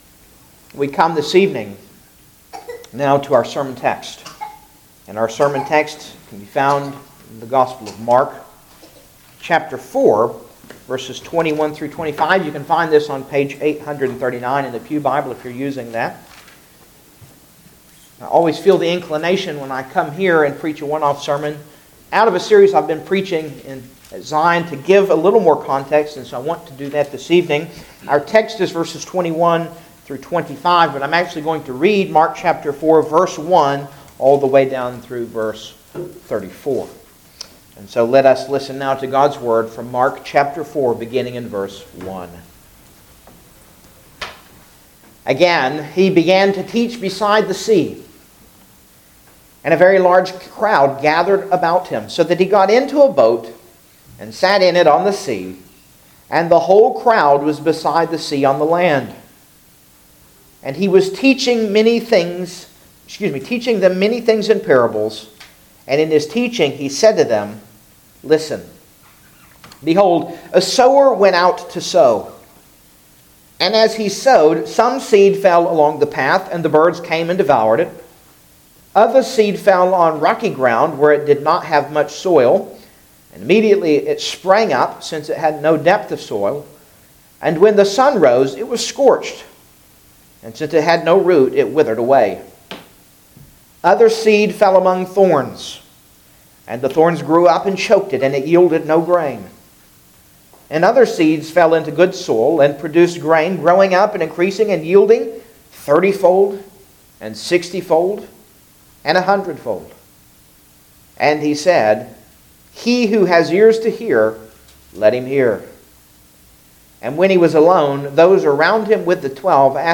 Gospel of Mark Passage: Mark 4:21-25 Service Type: Sunday Evening Service Download the order of worship here .